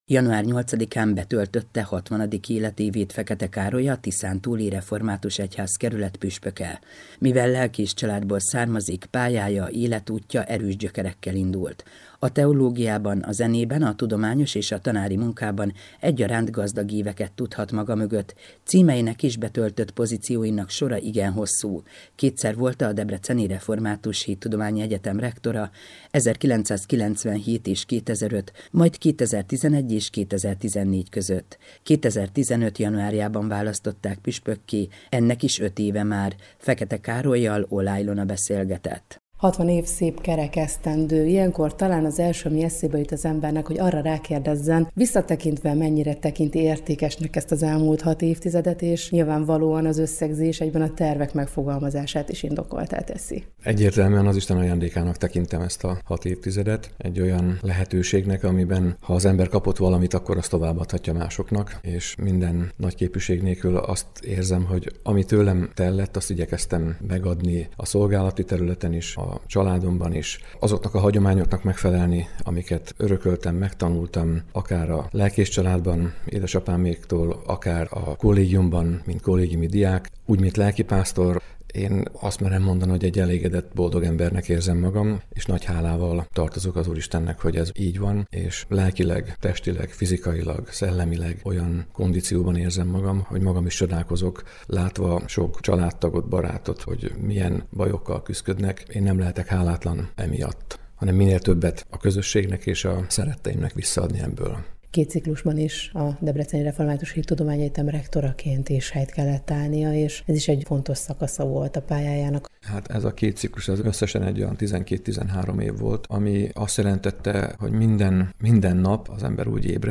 interjút az Európa Rádióban